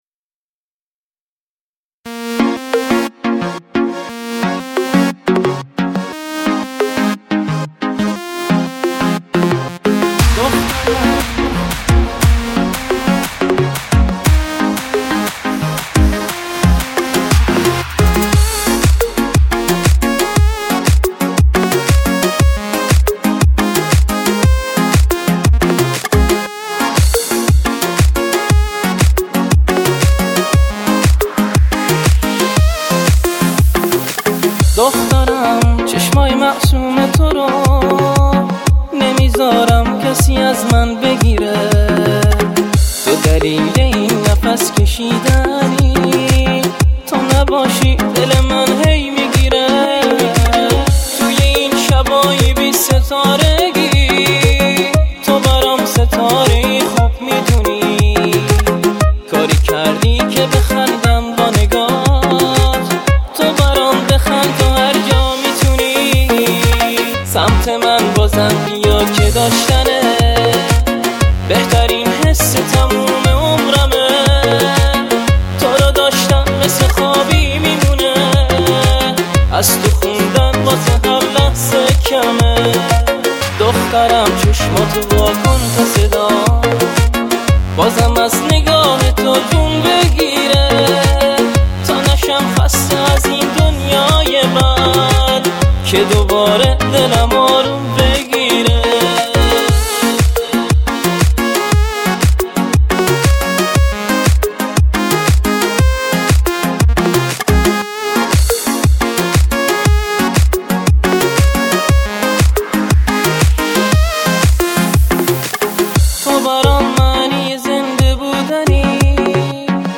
نماهنگ شاد دخترانه